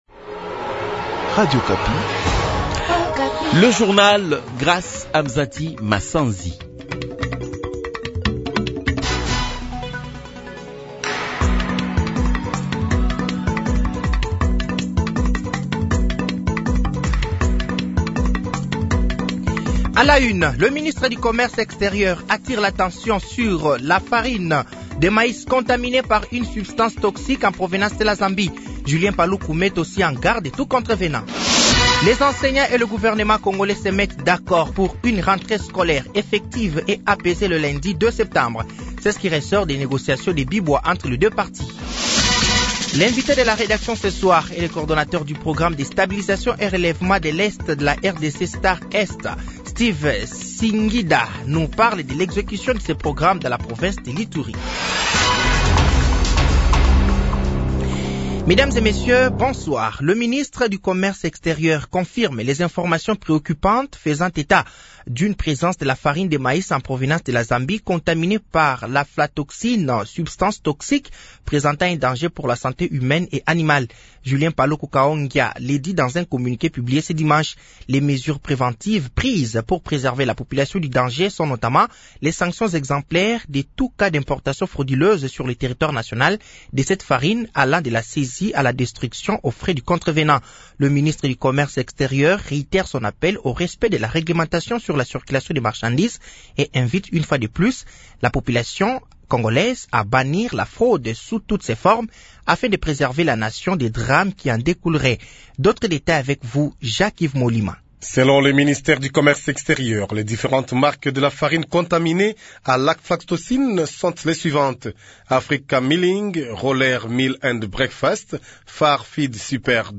Journal français de 18h de ce dimanche 25 août 2024